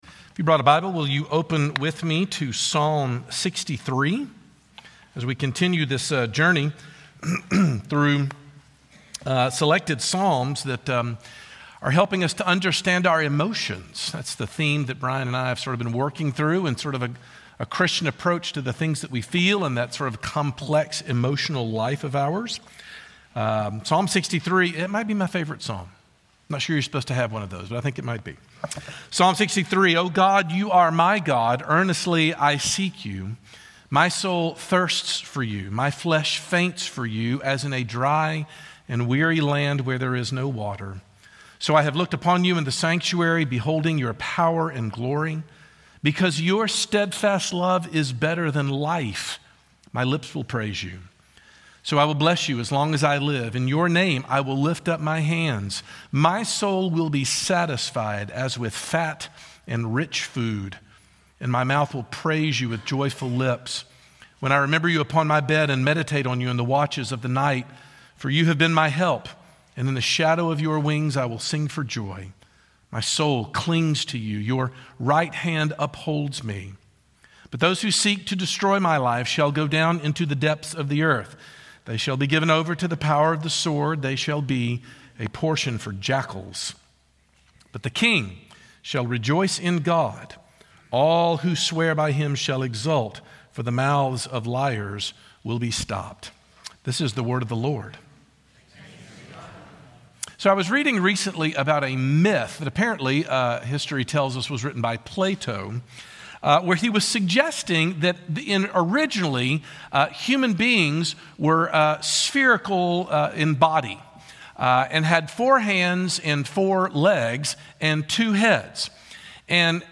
Scripture Reference: Psalm 63 CS Lewis is an expert on the deep longing of the soul (what he called "Joy") and how important it is to understanding the human condition. But David has found the ultimate fountain of joy in God, and investigating it opens up all kinds of doors to our humanity. Sermon Points